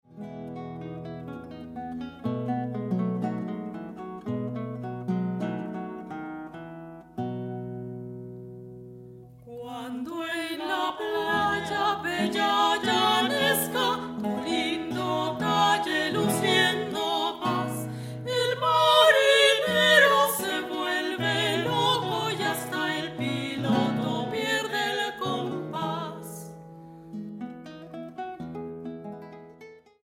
guitarras